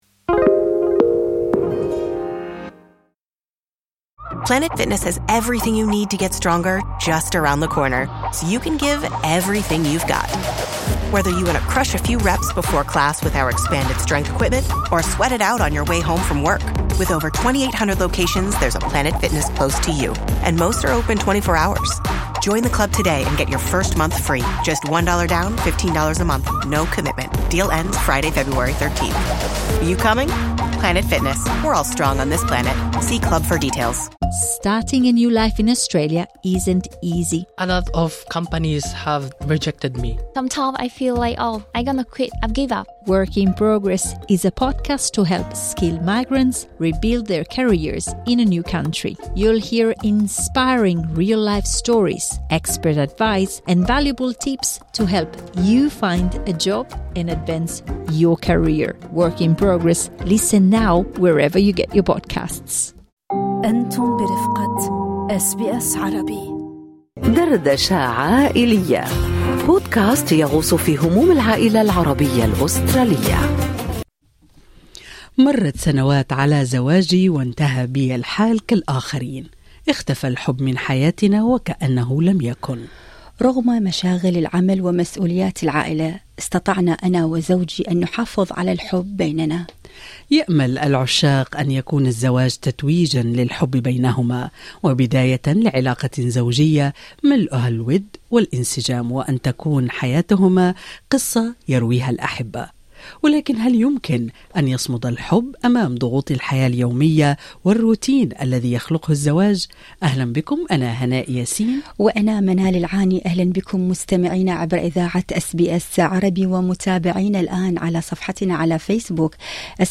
دردشة عائلية: كيف نمنع الزواج من ان يتحول إلى مقبرة للحب؟ مستشارة عائلية تجيب